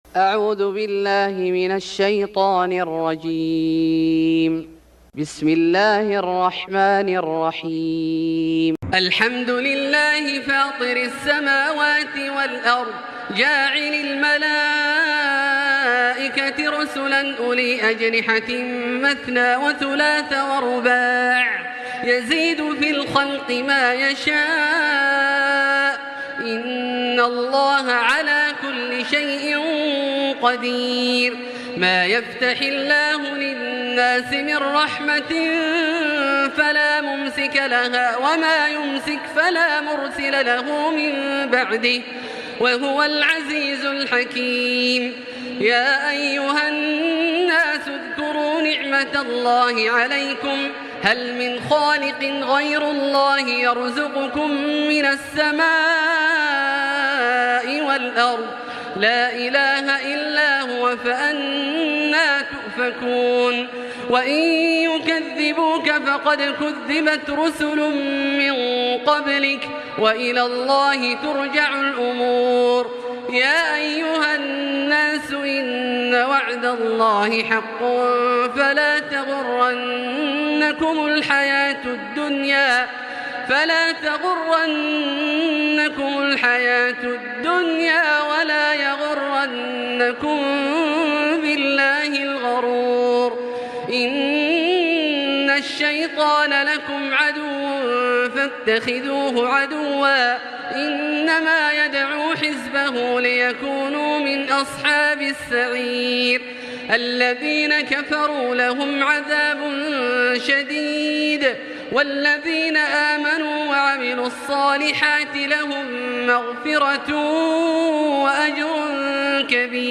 سورة فاطر Surat Fatir > مصحف الشيخ عبدالله الجهني من الحرم المكي > المصحف - تلاوات الحرمين